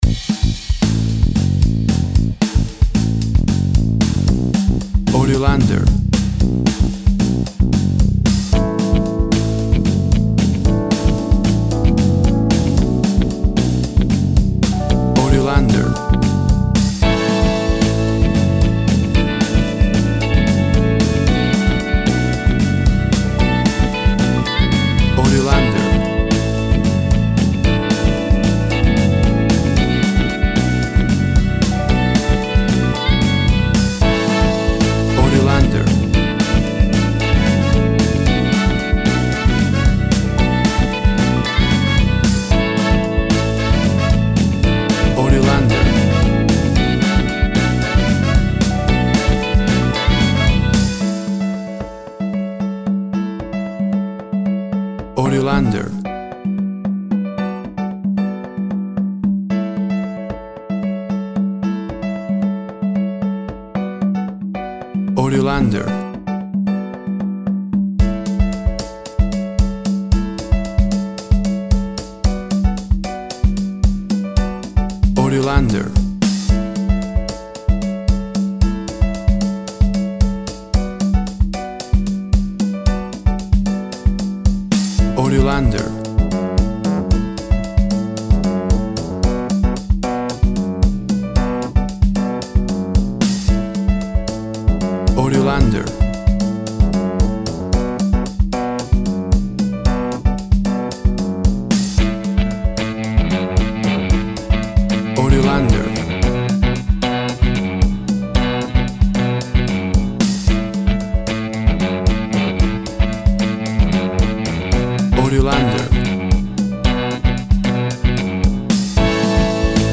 fun,upbeat, and care free with an indie pop rock style sound
Tempo (BPM) 113